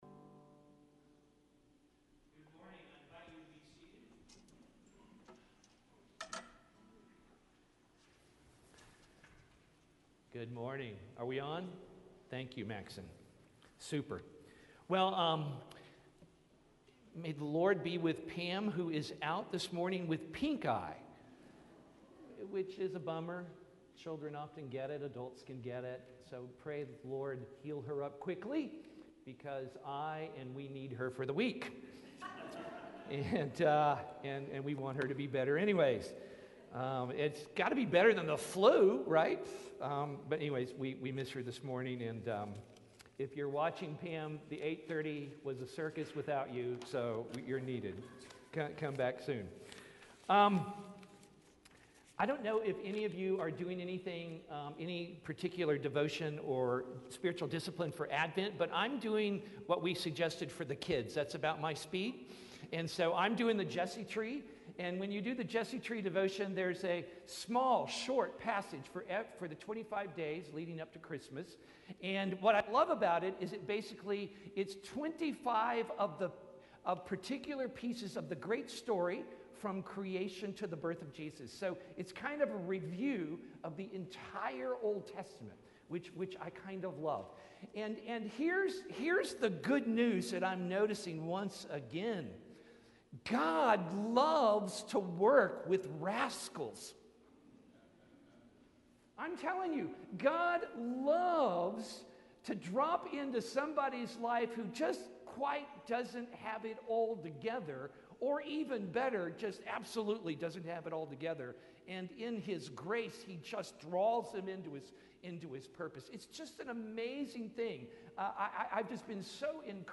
Apostles Anglican Church - Lexington, KY